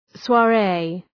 Shkrimi fonetik {swɑ:’reı}